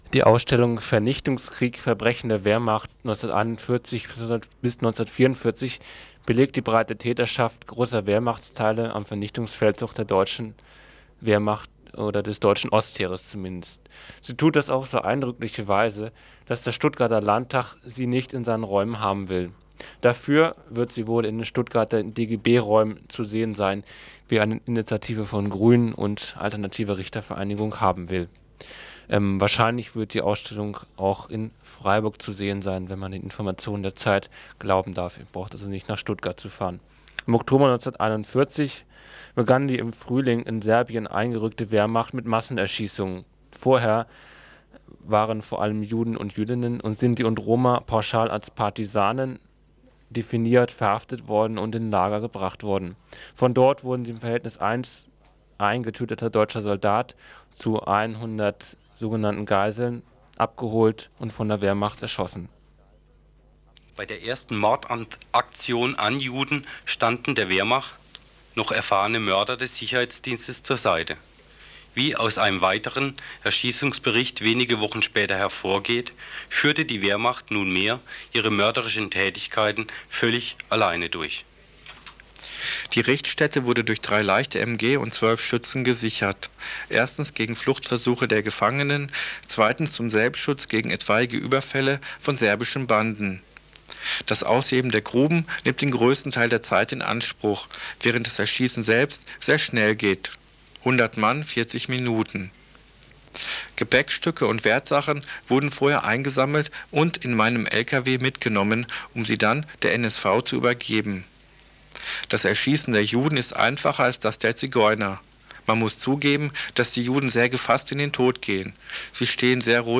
Fr 19.05.95 2. TI Landtag Stuttgart lehnt Ausstellung zur Verbrechen der Wehrmacht ab, Interview mit Grünem Michael Jacobi